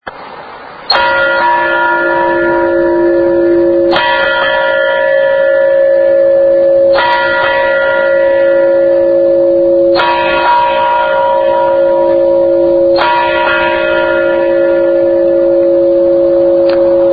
This church installed a microphone about eight feet from the bell in their steeple and ran it thru their PA system soundboard.
Double Ring (Ding – Dong)